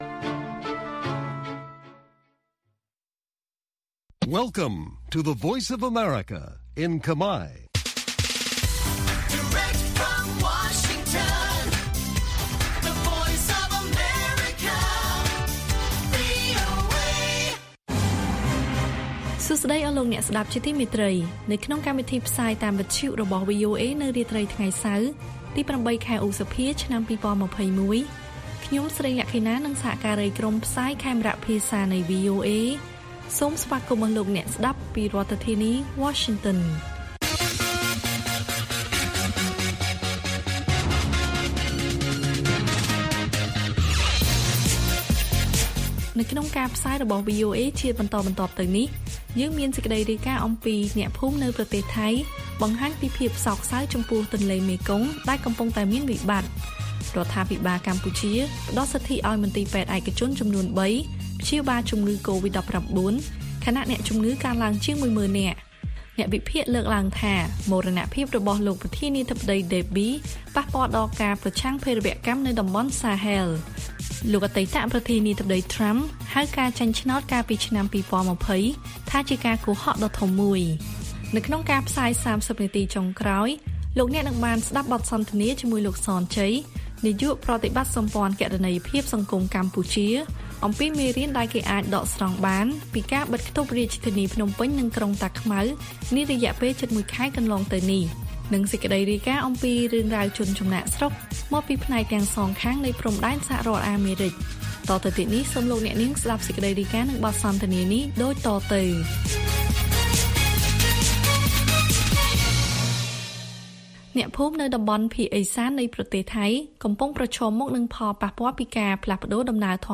ព័ត៌មានពេលរាត្រី៖ ៨ ឧសភា ២០២១